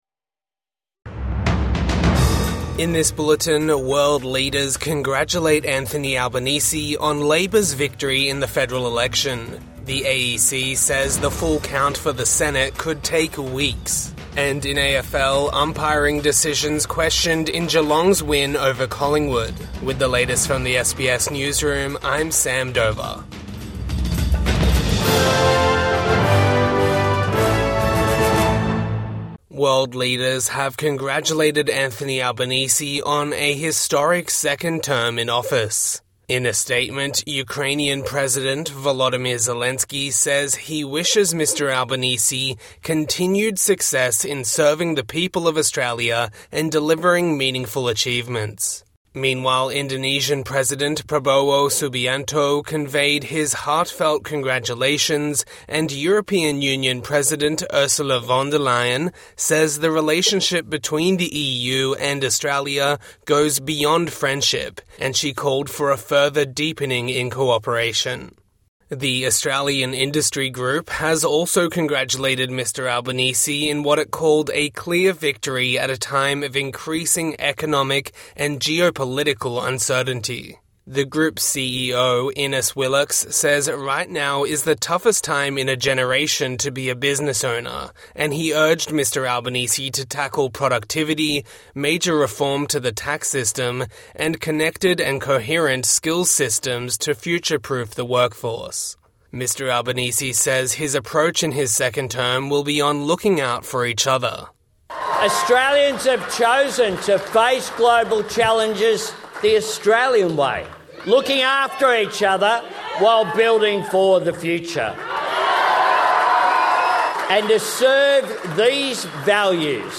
World leaders congratulate Anthony Albanese for election win | Midday News Bulletin 4 May 2025